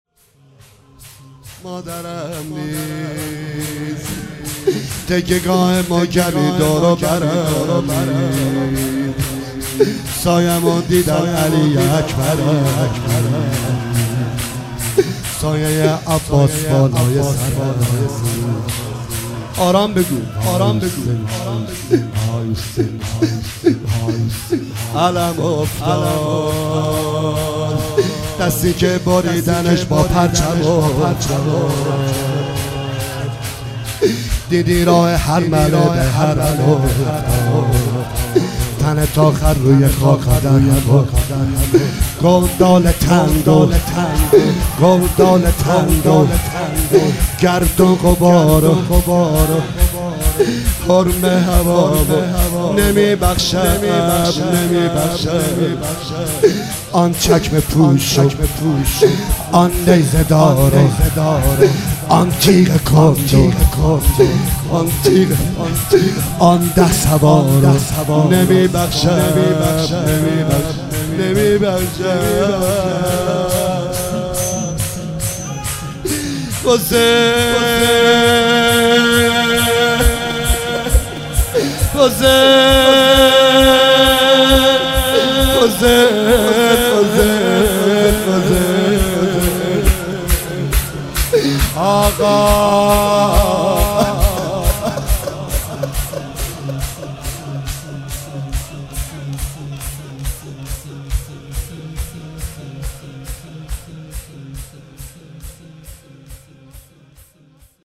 دانلود مداحی مادرم نیست تکیه گاه محکمی دور و برم نیست